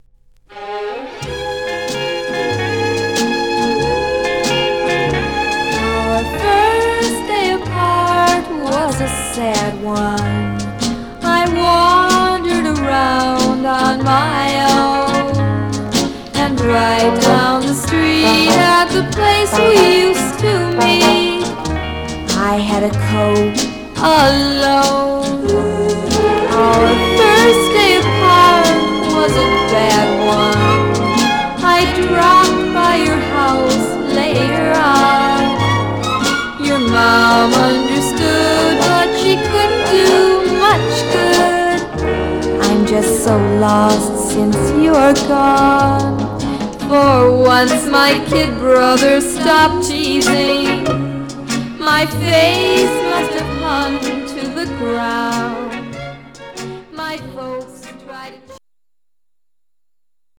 Stereo/mono Mono
White Teen Girl Groups